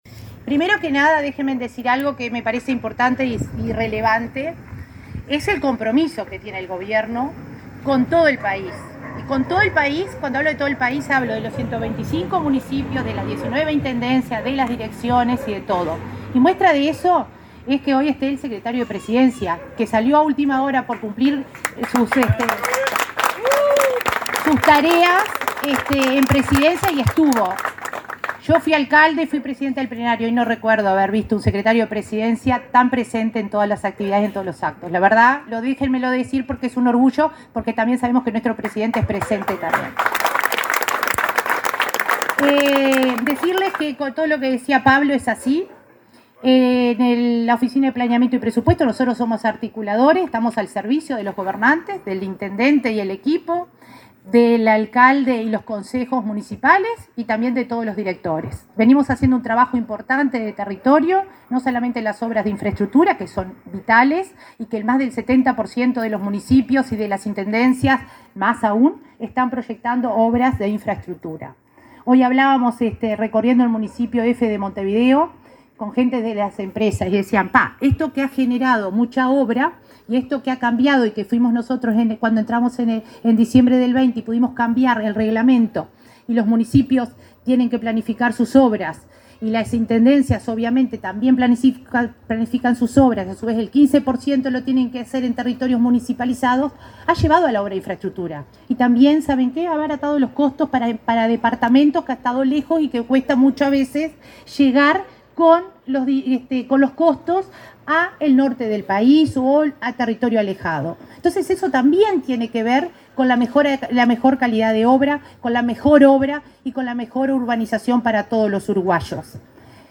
Palabras de autoridades en inauguración en departamento de Treinta y Tres
La directora de Descentralización de la OPP, María de Lima, y el secretario de Presidencia, Álvaro Delgado, destacaron la importancia de esta infraestructura.